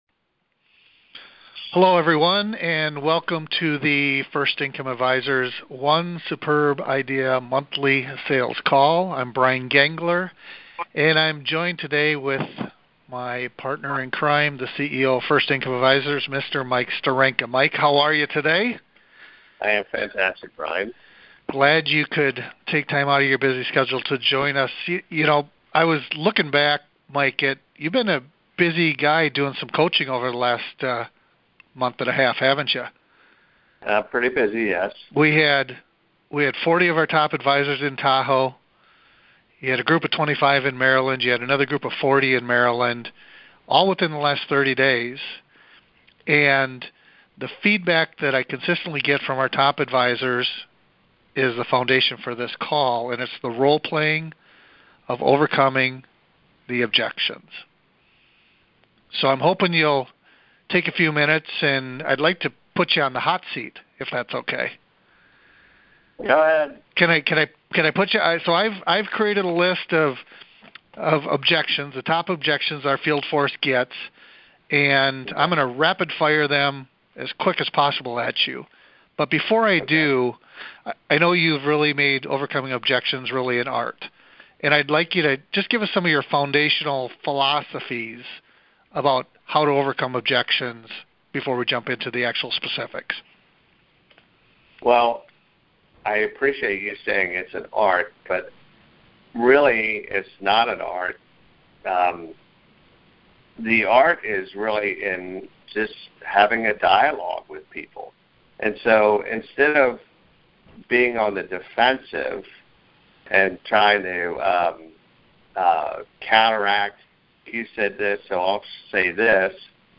role play though the most common objections.